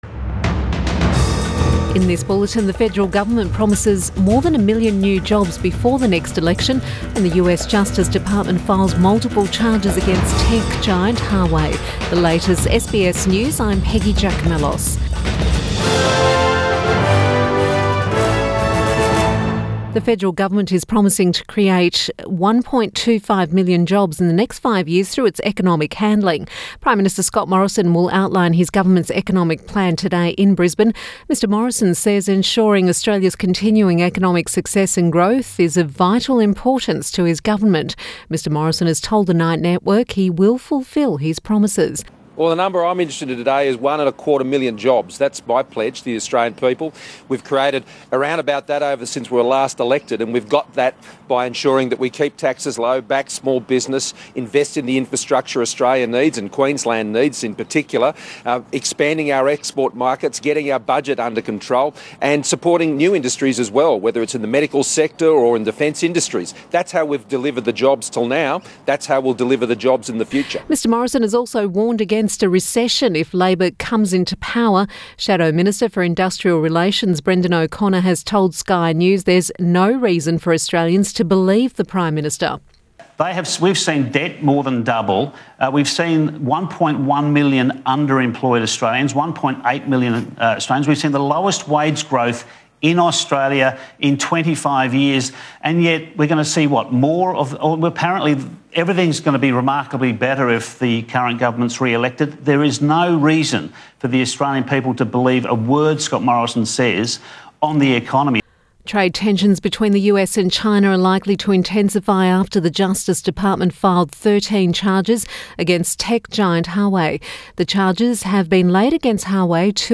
Midday Bulletin Jan 29